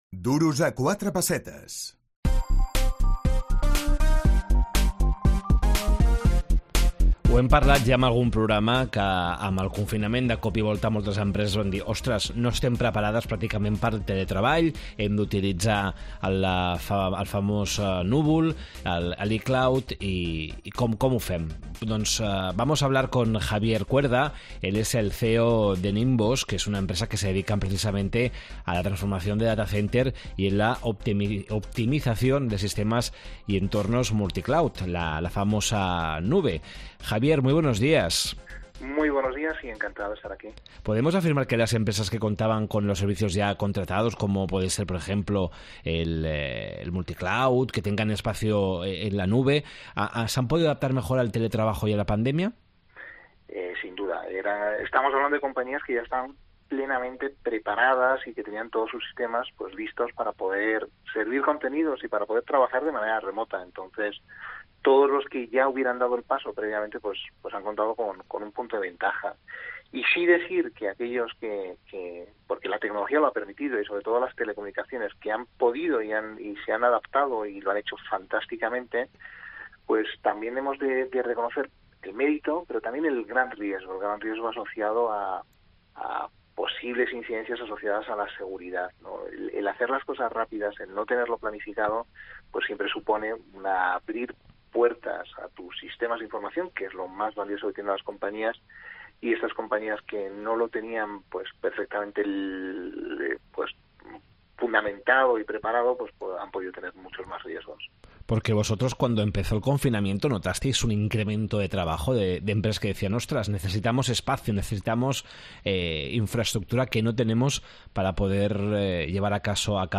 Duros a quatre pessetes, el programa d’economia de COPE Catalunya i Andorra.